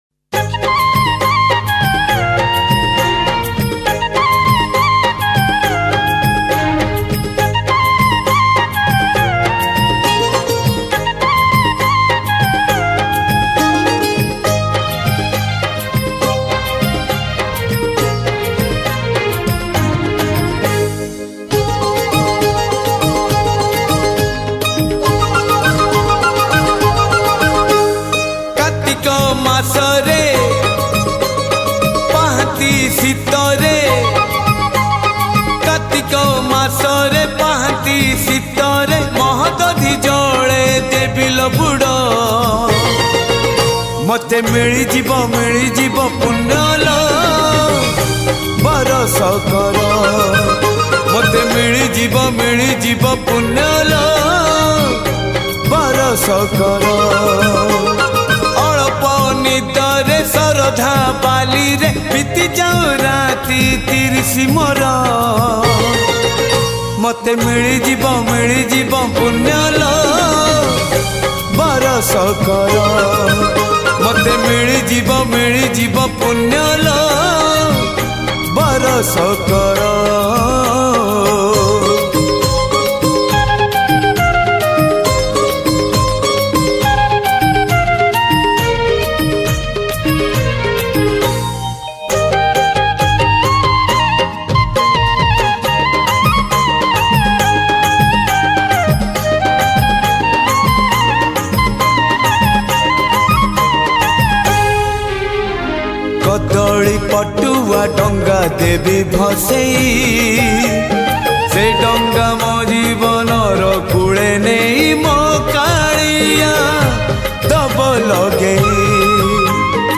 Category : Kartik Purnima Song